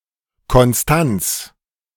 Constanza[1] (en alemánKonstanz[kɔnˈstant͡s] (AFI)De-Konstanz.ogg
De-Konstanz.ogg